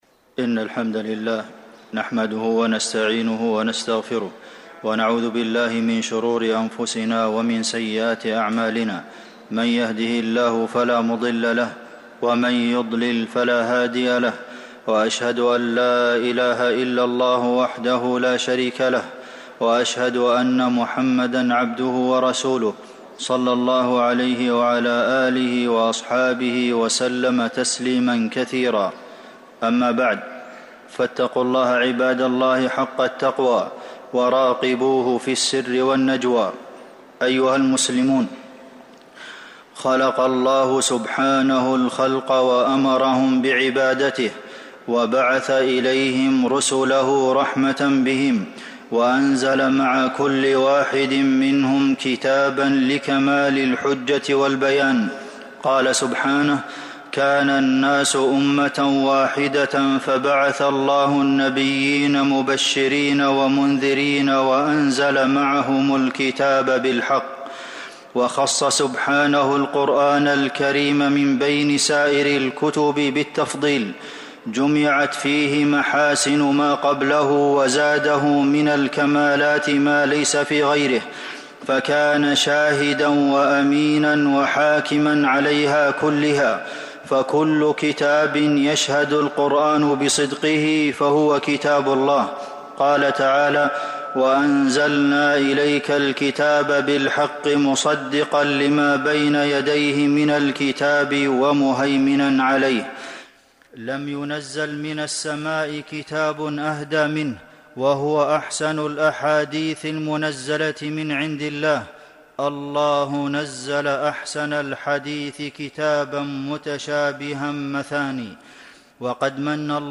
المدينة: فضائل بعض آيي وسور القرآن الكريم - عبد المحسن بن محمد القاسم (صوت - جودة عالية